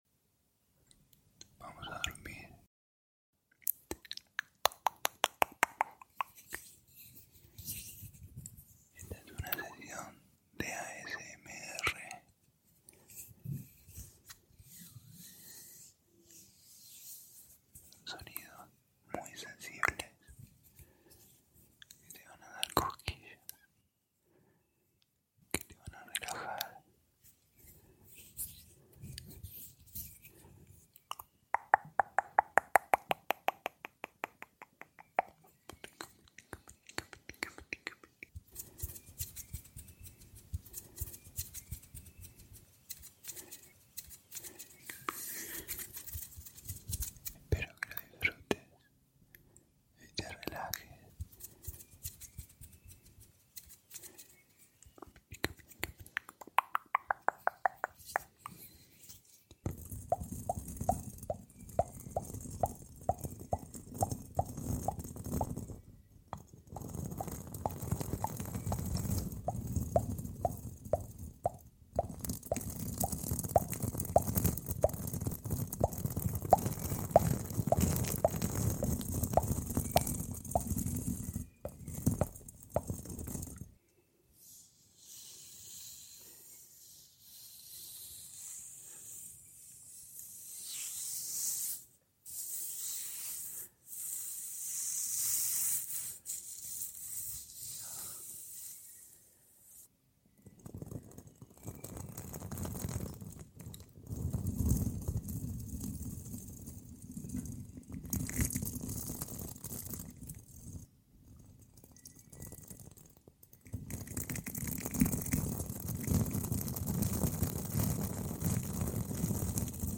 ASMR para dormir - Barba y gotas